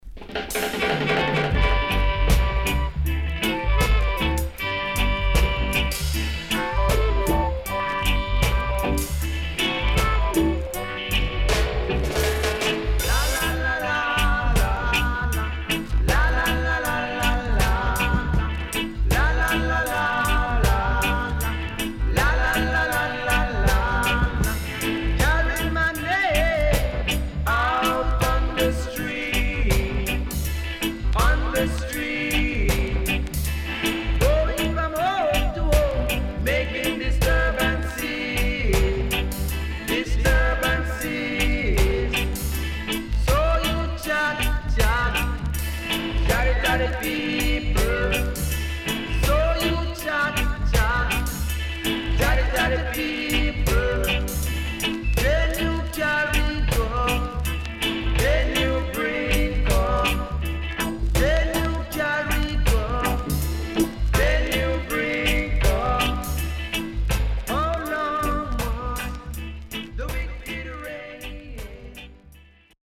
Very Rare.激渋Rocksteady Vocal 後半Dubwise接続
SIDE A:少しジリっとしたノイズ入ります。